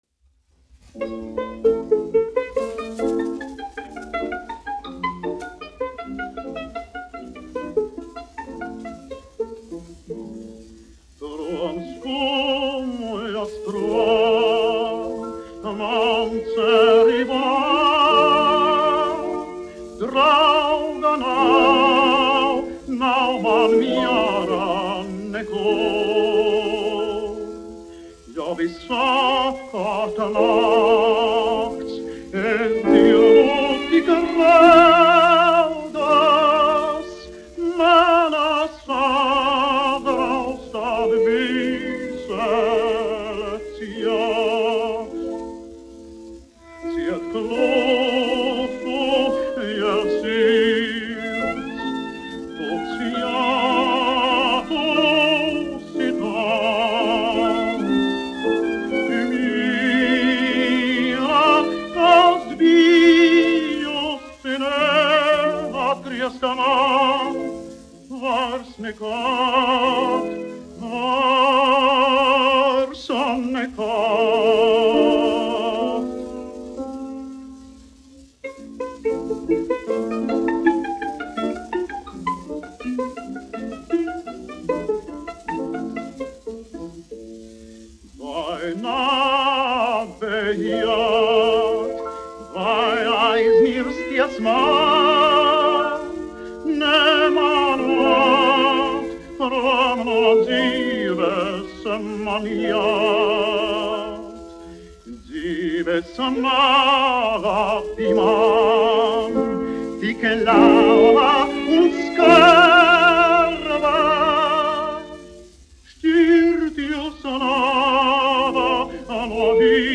1 skpl. : analogs, 78 apgr/min, mono ; 25 cm
Romances (mūzika)
Skaņuplate